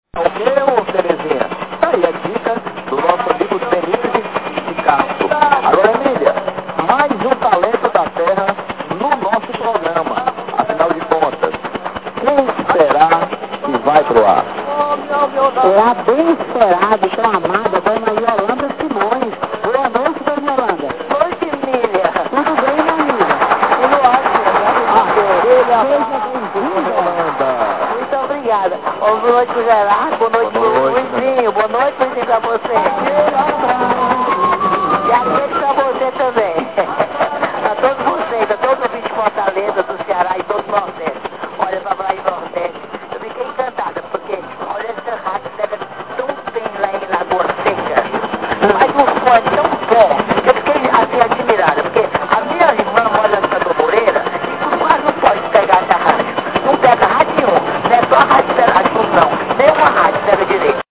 RGP3 Loop de Ferrite